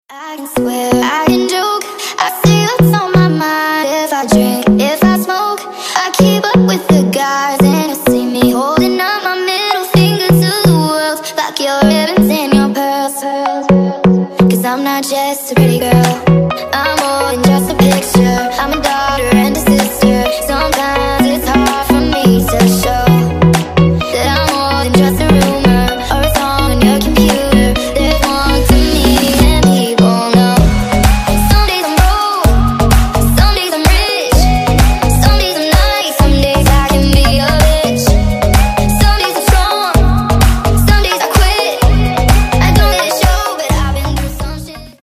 • Качество: 192, Stereo
поп
женский вокал
dance
Electronic
future house
club
vocal